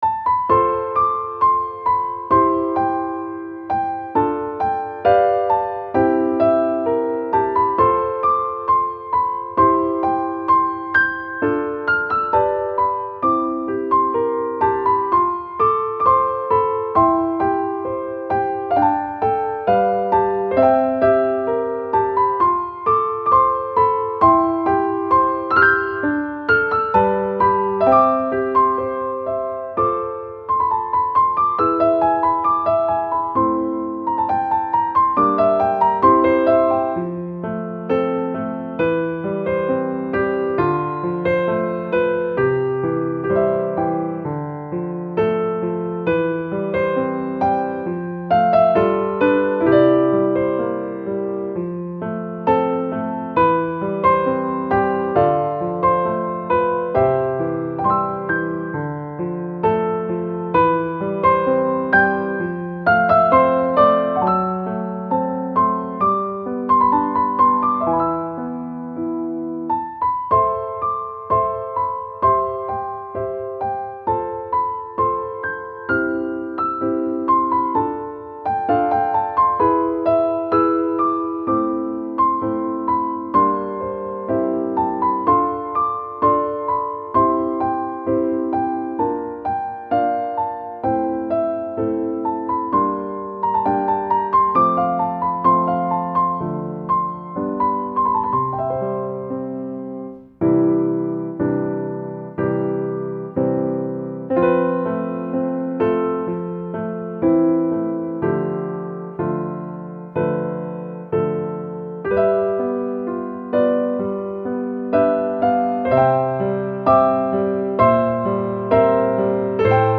-oggをループ化-   切ない しっとり 3:05 mp3